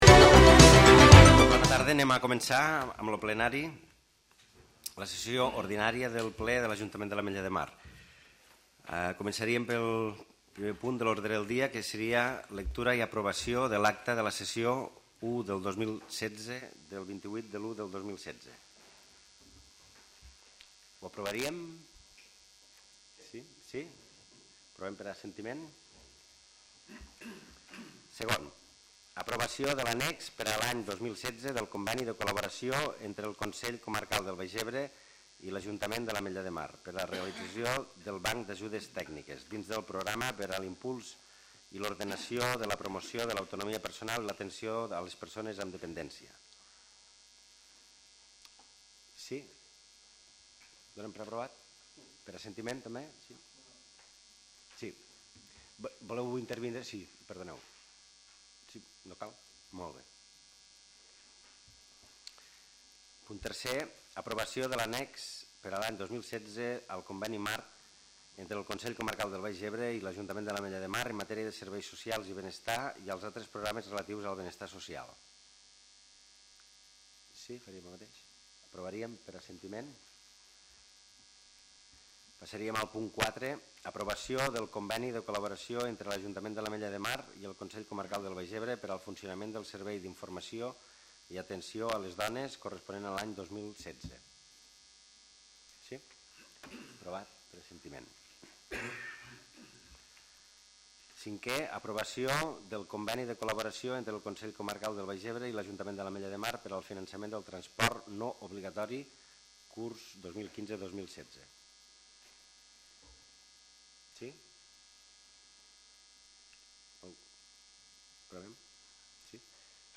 Àudio del Ple de l'Ajuntament de l'Ametlla de Mar, d'avui 31 de març de 2016, amb 16 punts a l'ordre del dia, més precs i preguntes.